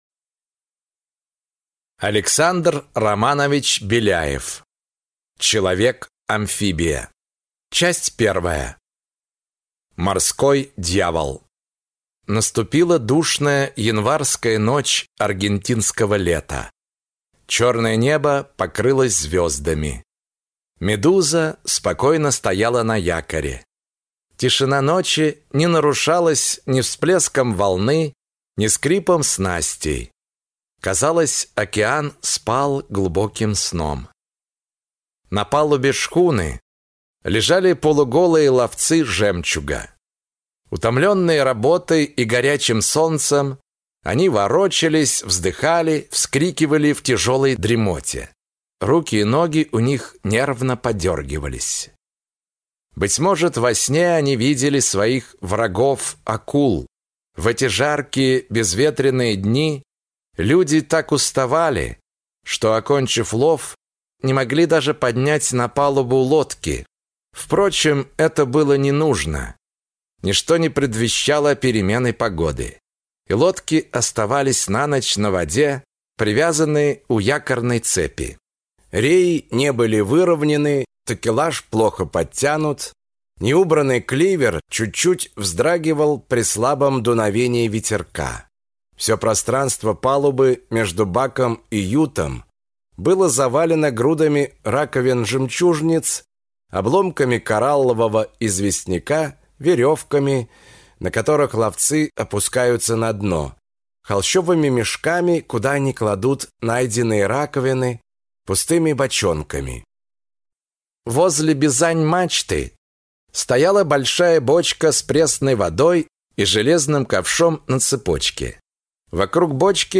Аудиокнига Человек-амфибия. Голова профессора Доуэля | Библиотека аудиокниг